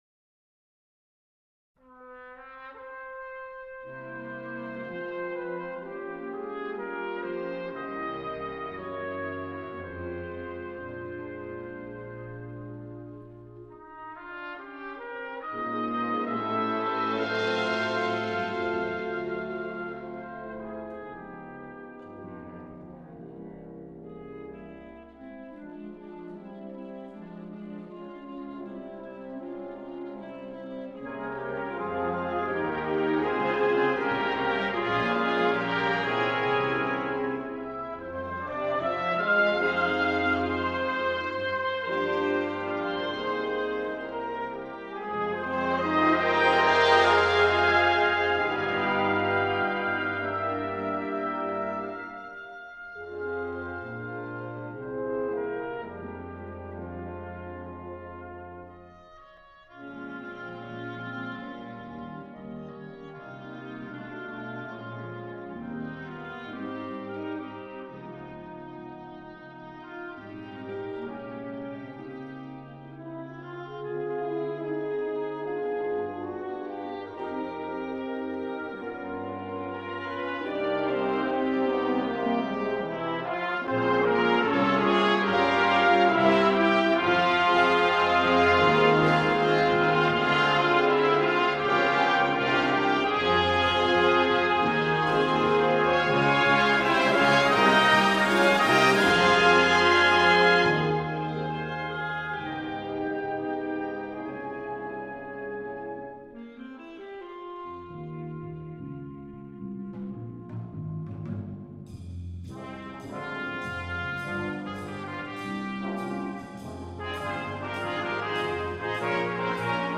for Wind Band
Instrumentation: Wind Band